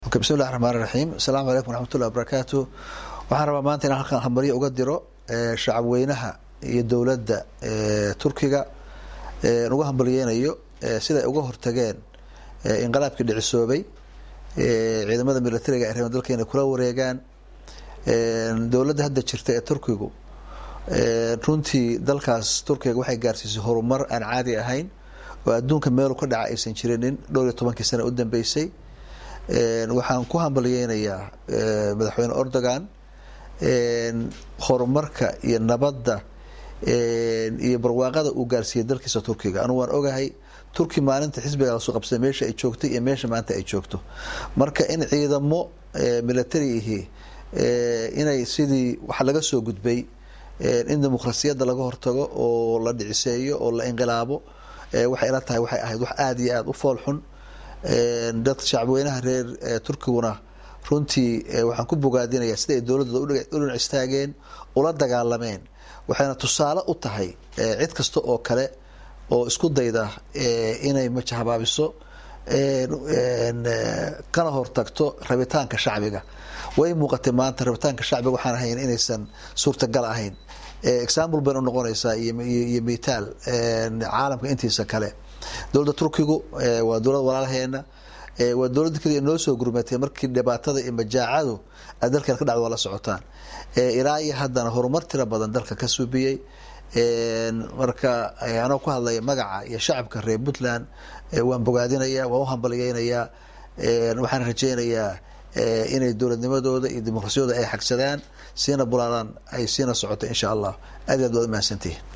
Dhagayso madaxweyne Gaas
Codka-Madaxweynaha-21.mp3